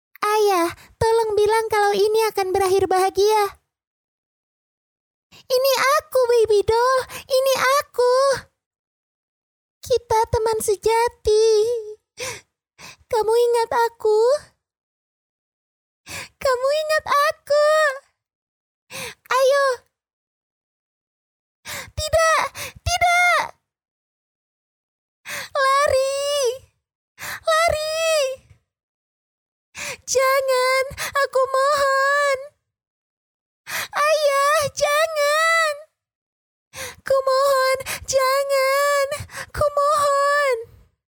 Voice actor sample
차분/편안